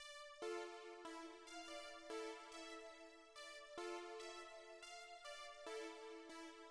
Macros_Pluck.wav